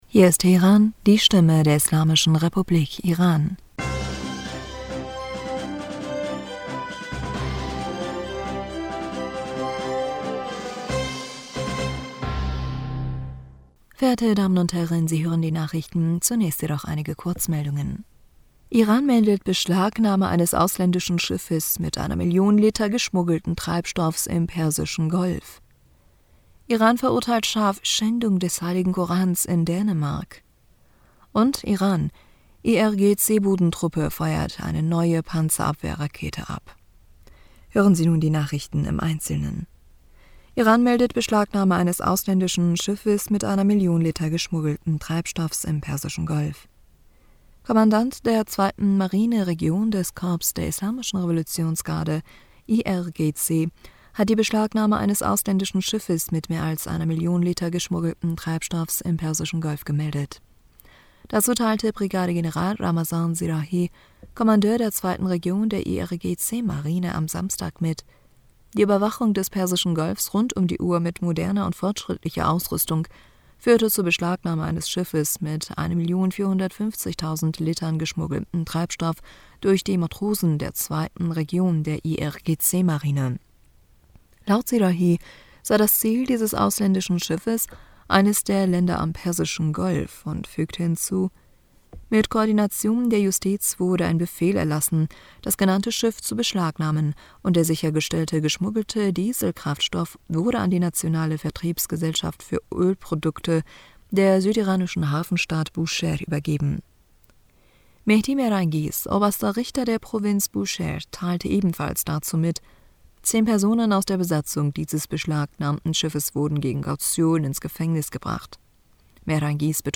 Nachrichten vom 16. April 2023
Die Nachrichten von Sonntag, dem 16. April 2023